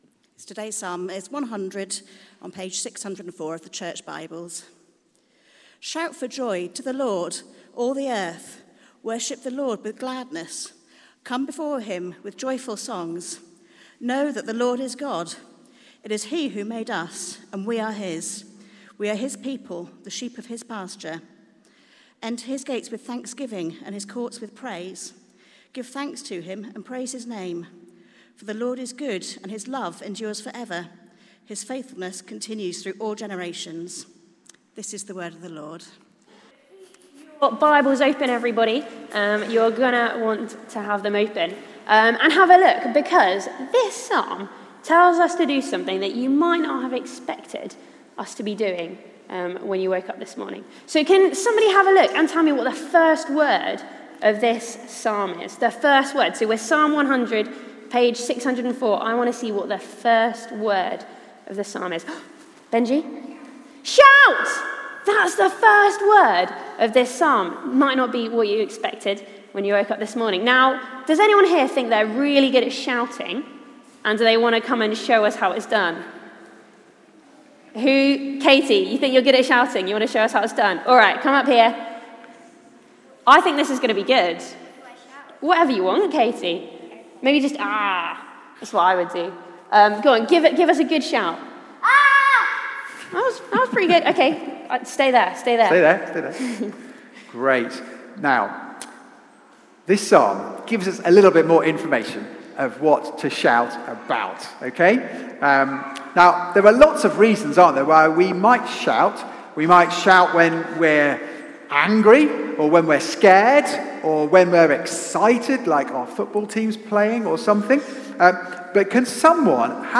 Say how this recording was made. Media for Service (10.45) on Sun 19th Sep 2021 10:45